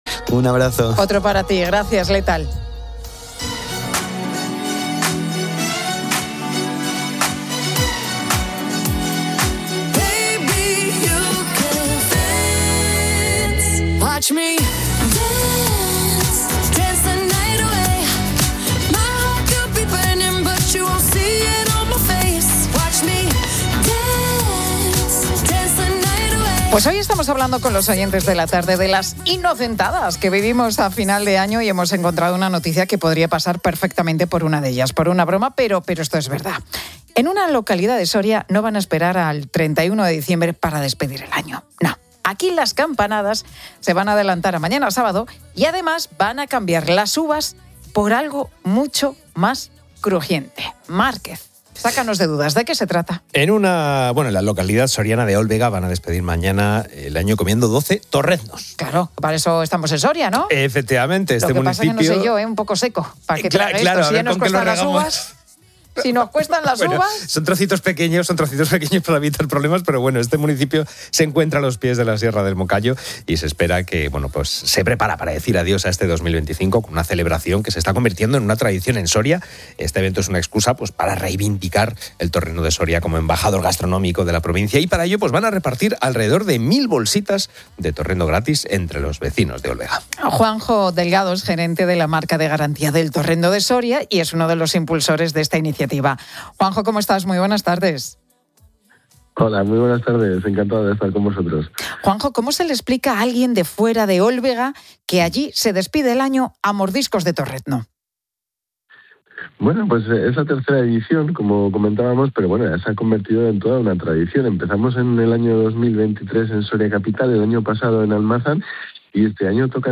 Lo que podría parecer una inocentada de fin de año es una realidad en la provincia de Soria.